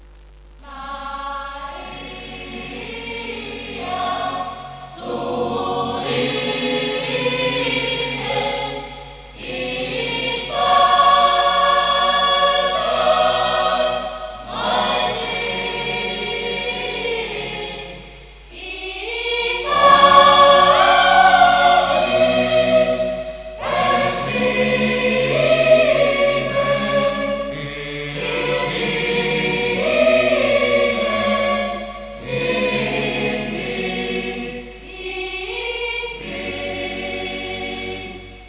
Canto armonizzato
wav file of Maria zu lieben (per sentire il canto corale) (to hear the song)